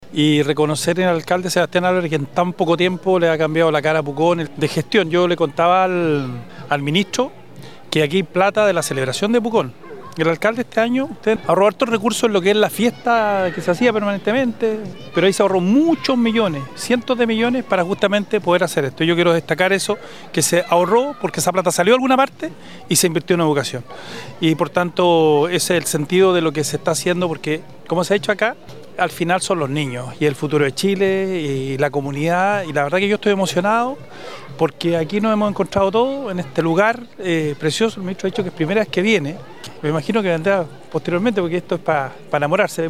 Andres-Joaunnet-diputado-valora-la-gestion-municipal.mp3